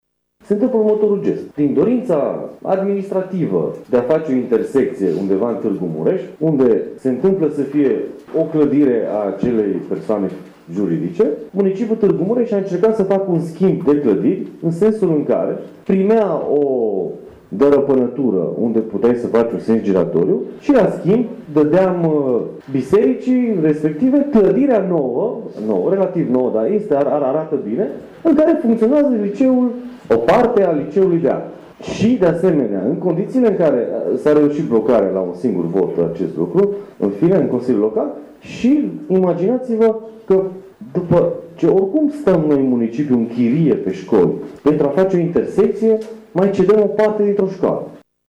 Așa a afirmat consilierul local din Tîrgu-Mureș, social-democratul Olimpiu Sabău-Pop, în cadrul lucrărilor Universităţii de Vară de la Izvoru Mureşului.